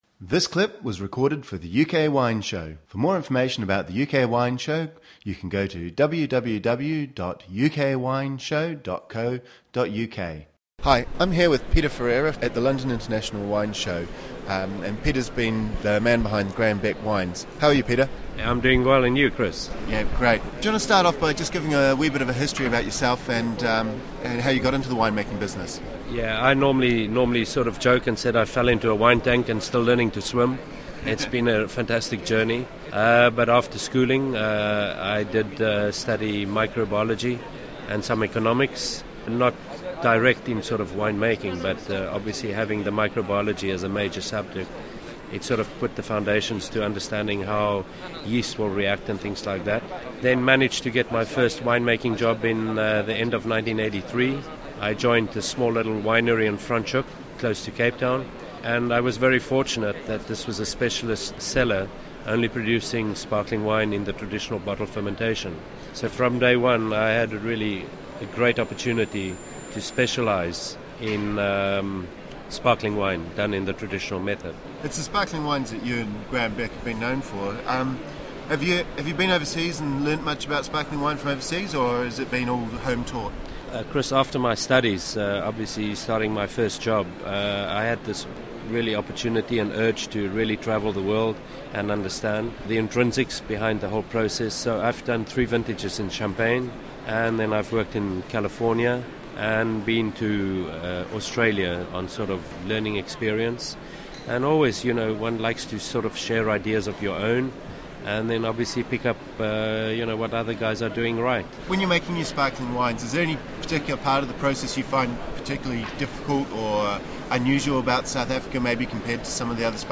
» Listen to the full UK Wine Show